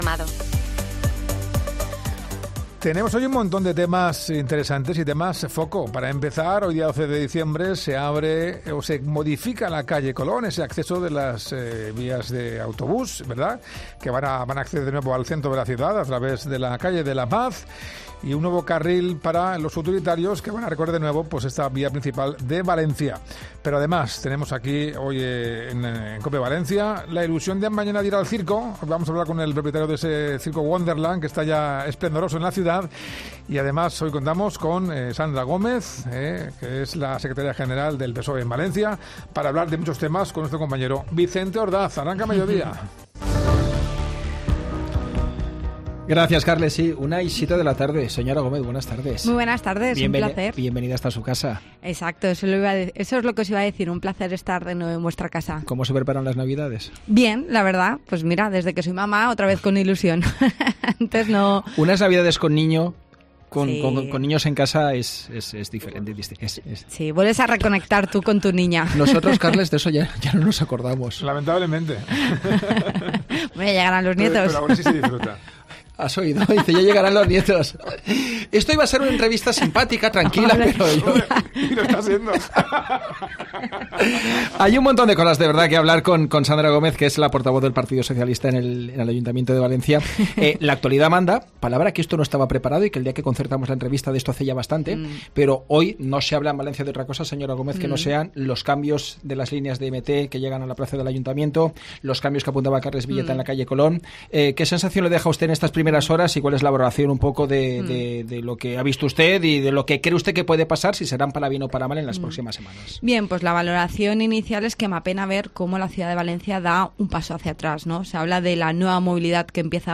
Sandra Gómez, portavoz del PSPV en el Ayuntamiento de Valencia, ha pasado por los micrófonos de COPE, coincidiendo con el estreno de las líneas de...